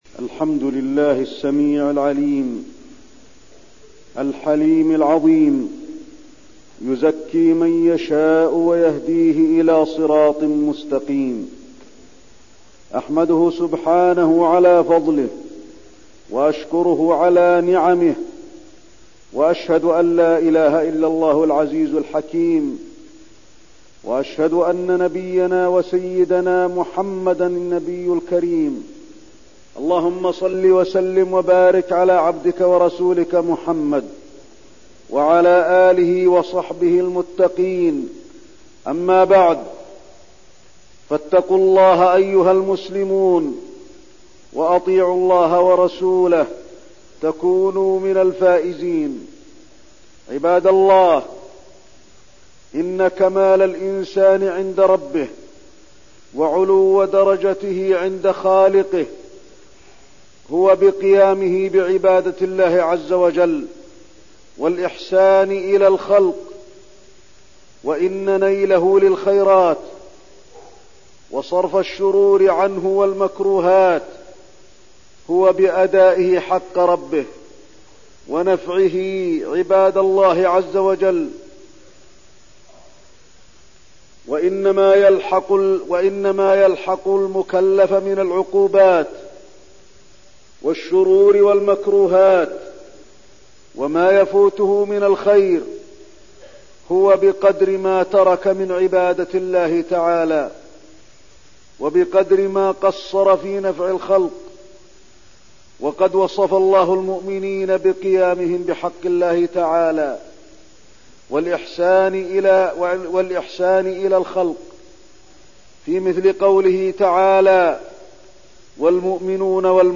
تاريخ النشر ١٨ شعبان ١٤١٢ هـ المكان: المسجد النبوي الشيخ: فضيلة الشيخ د. علي بن عبدالرحمن الحذيفي فضيلة الشيخ د. علي بن عبدالرحمن الحذيفي الزكاة